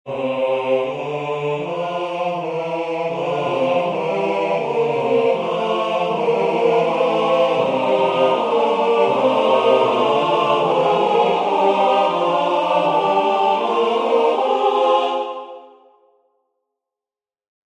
I am writing a polytonal 4 voice canon, where each voice is in a different key like this: Soprano: Eb major Alto: Bb major Tenor: F major Bass: C major But, I'm not sure how to go about the fourth bar, where the Soprano voice enters.